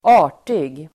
Uttal: [²'a:r_tig]